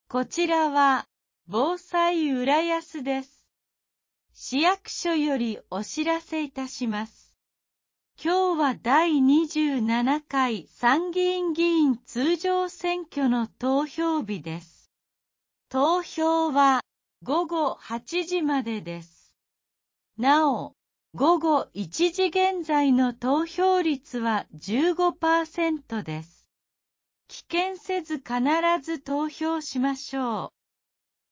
第27回参議院議員通常選挙 | 浦安市防災行政無線 放送内容掲載ホームページ